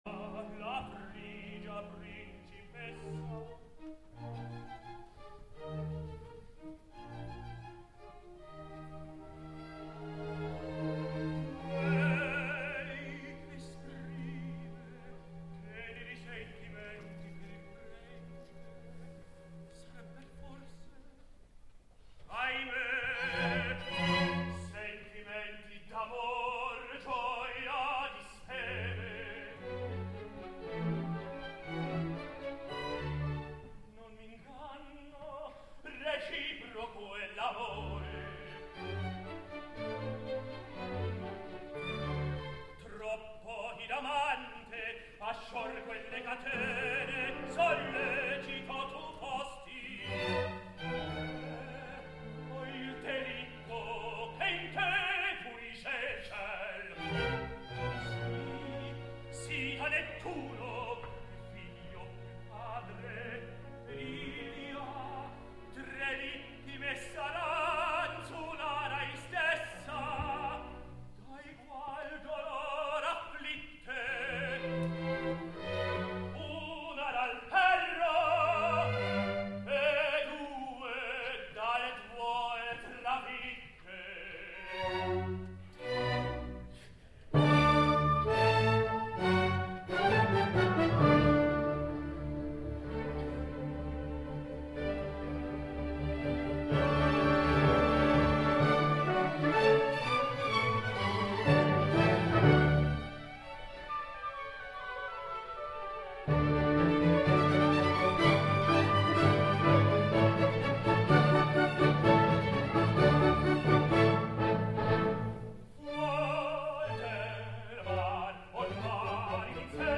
Del segon acte escoltem a Polenzani cantant “Fuor del mar”
ROH Covent Garden de Londres, 15 de novembre de 2014